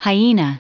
Prononciation du mot hyena en anglais (fichier audio)
Prononciation du mot : hyena